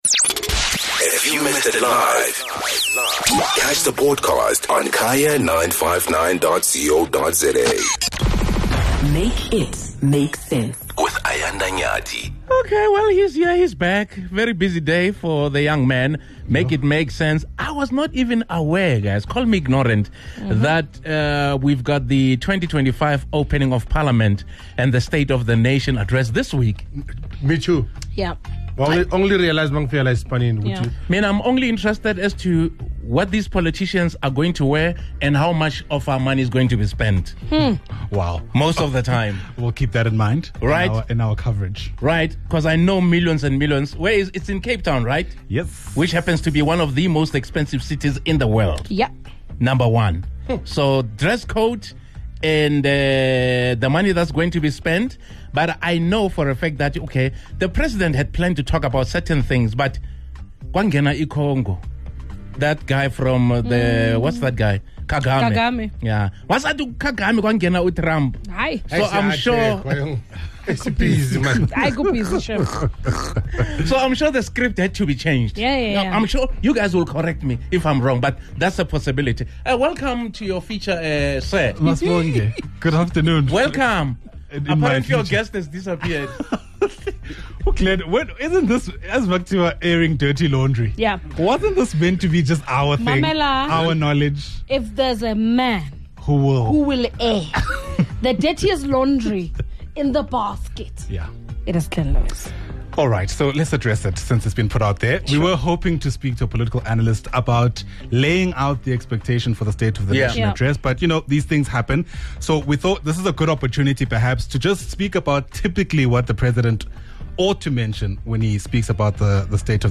The Drive 959 anchors and listeners shared their thoughts!